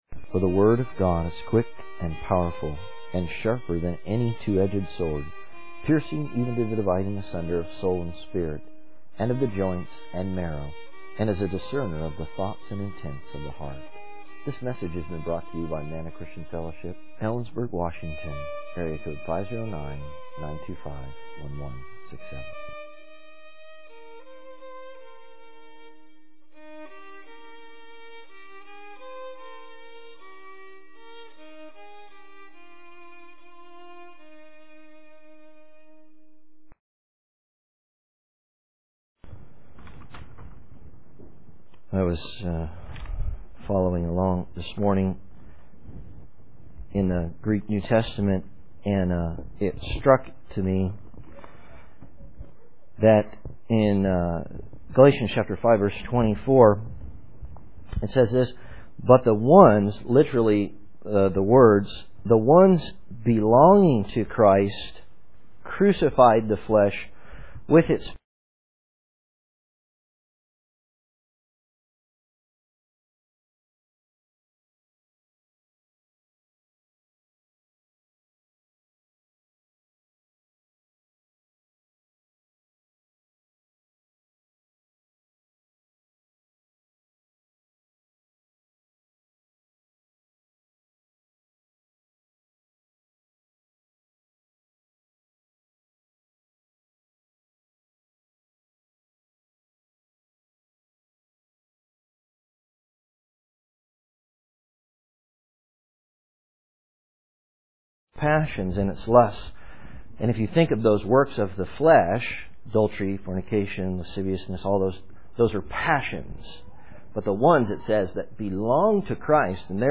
In this sermon, the speaker discusses the last words of Jesus on the cross.